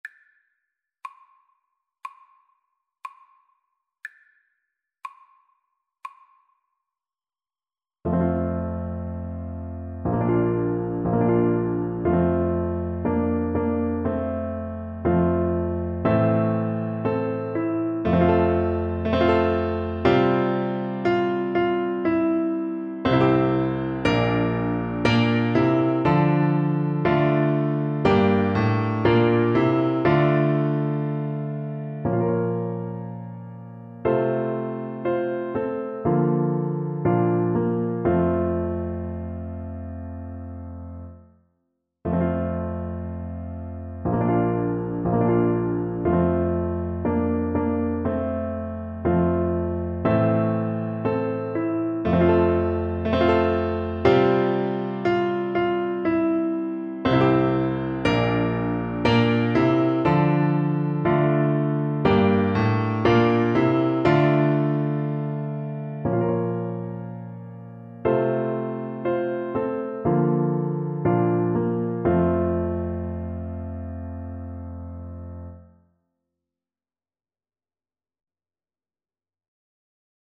4/4 (View more 4/4 Music)
Slow =c.60
Traditional (View more Traditional French Horn Music)